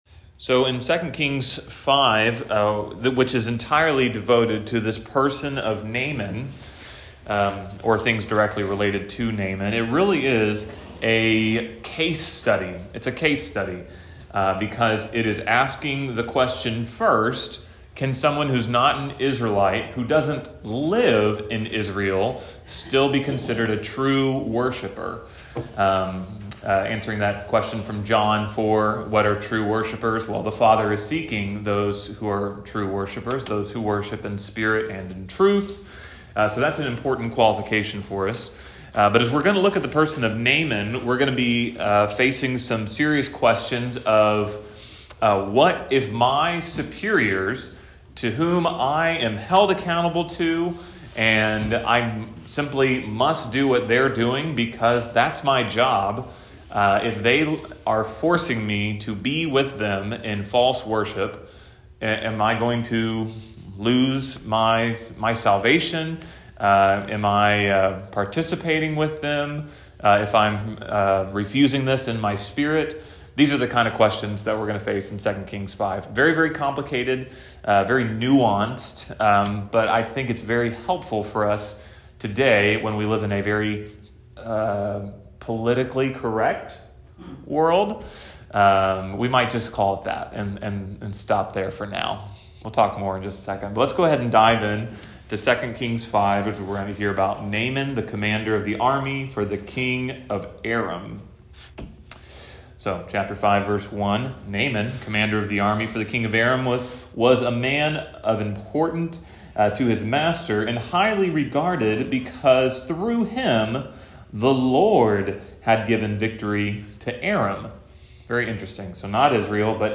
Wedensday Morning Bible Study 2 Kings 5